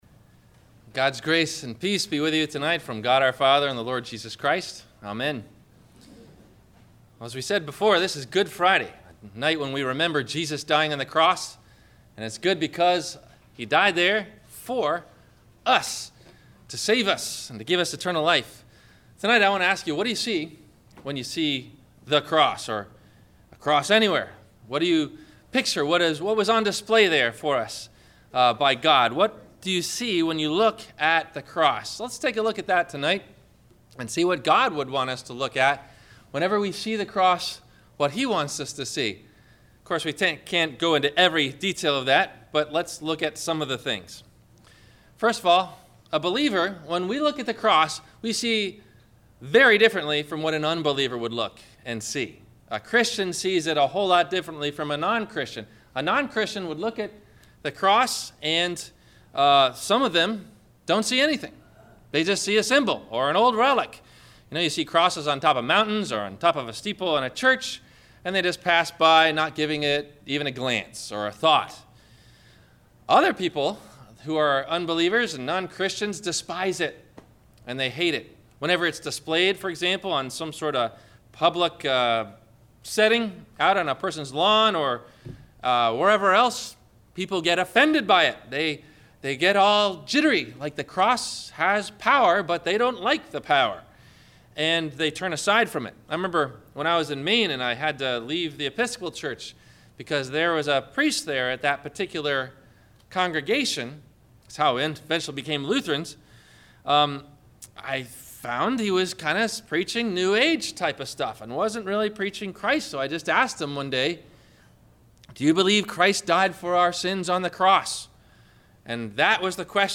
What Do You See when You See the Cross? – Good Friday – Sermon – April 03 2015